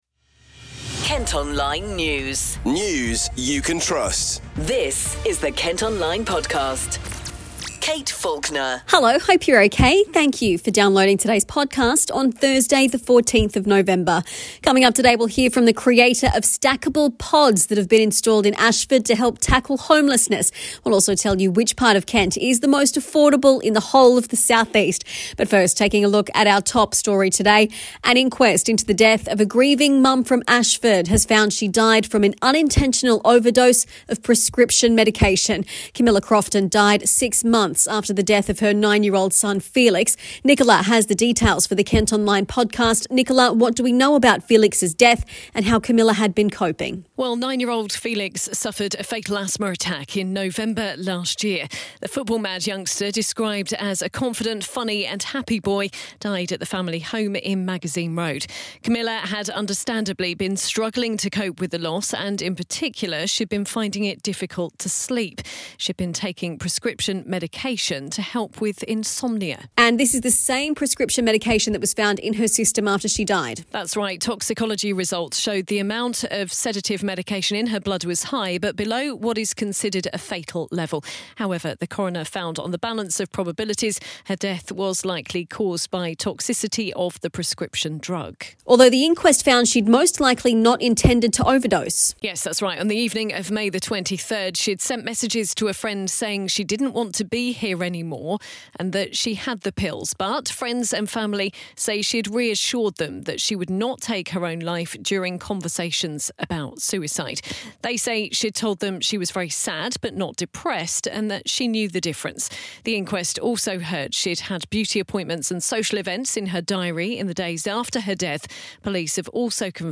Also in today’s podcast, you can hear the moment a knifeman in Chatham was detained by police after he threatened his neighbours for hanging party decorations.